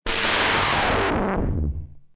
KART_turboStart.ogg